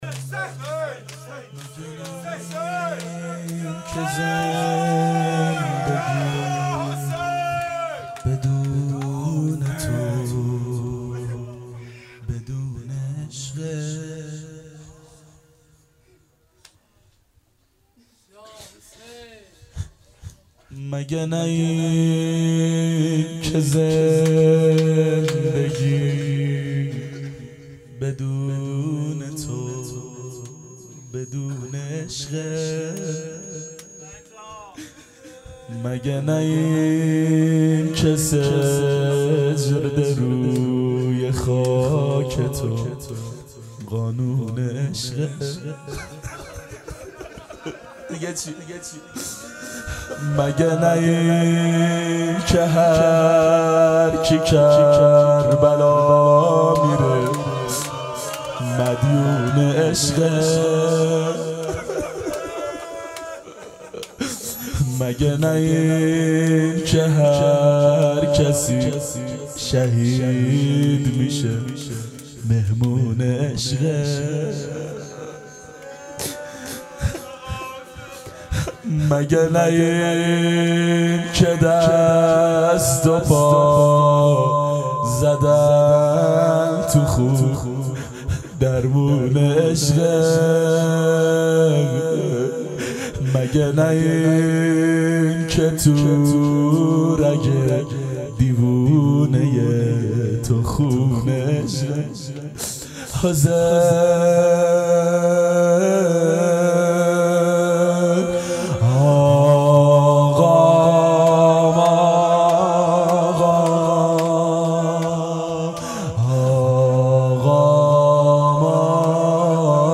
• دهه اول صفر سال 1390 هیئت شیفتگان حضرت رقیه س شب دوم (شب شهادت)